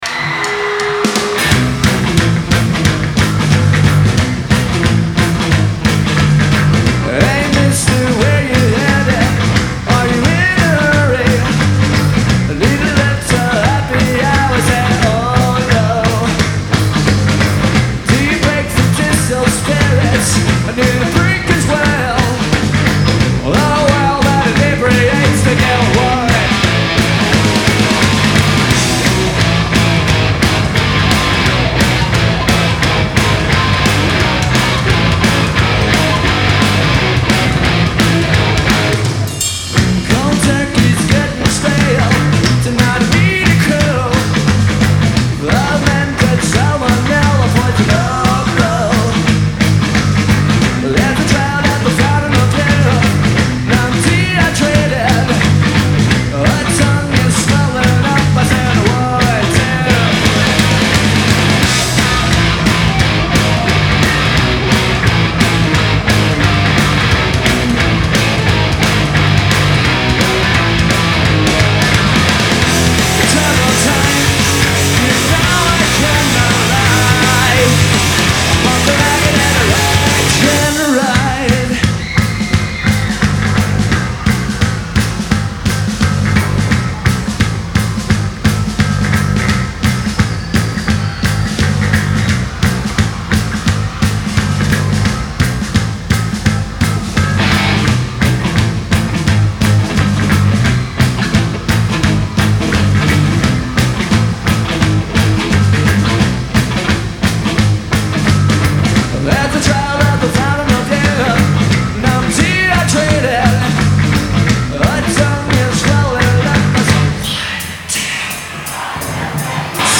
Live at the Electric Factory, Philadelphia 11/14/97